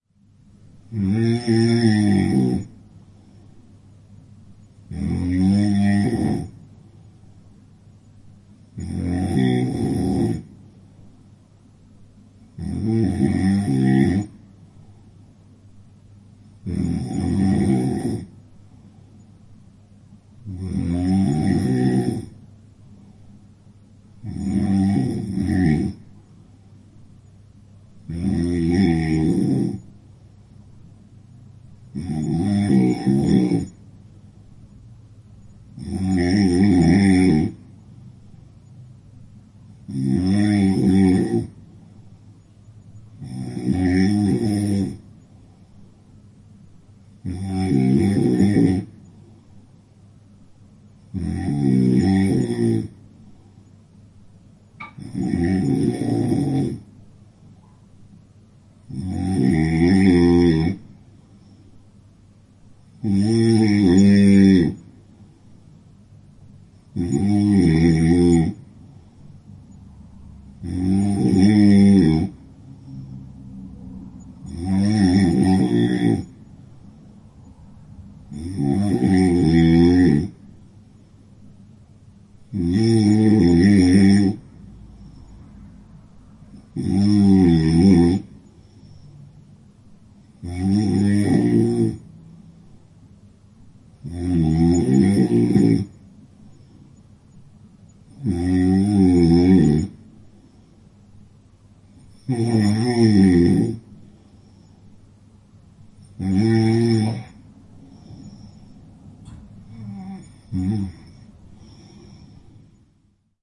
鼾声 收到
描述：温柔的打鼾。
Tag: 睡眠 打鼾 OWI